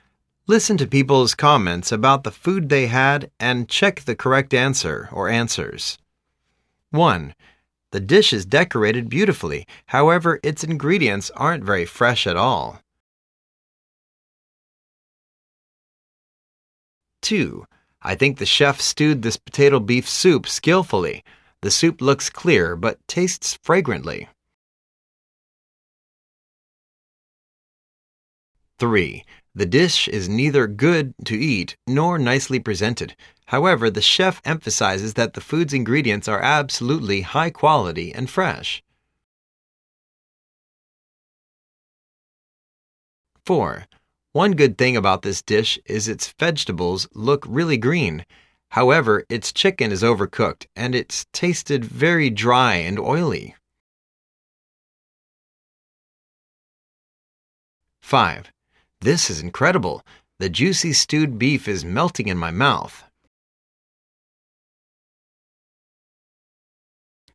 Listen to people's comments about the food they had and check the correct answer/answers.